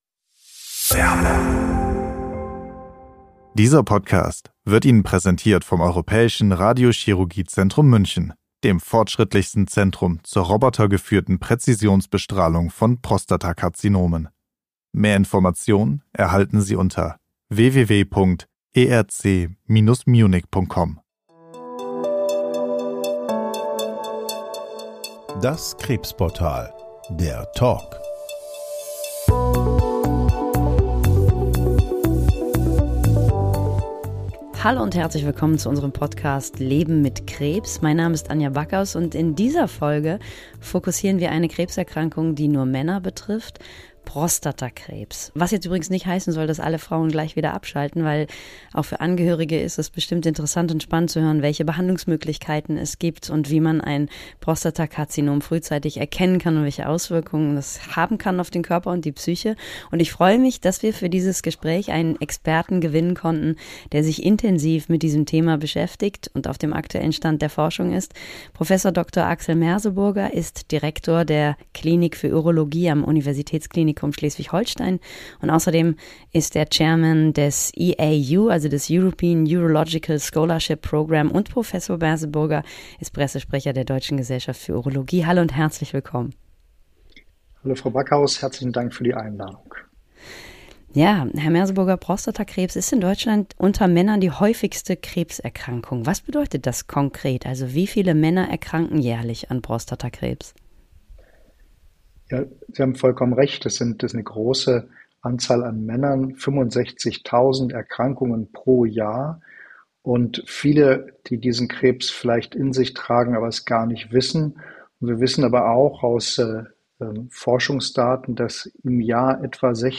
im Gespräch mit dem Prostatakrebs-Spezialisten zu guter Früherkennung, etablierten und neuen Therapien, dem Umgang mit der Erkrankung – und wann es trotz Diagnose sinnvoll ist, erstmal abzuwarten und keine Behandlung zu beginnen.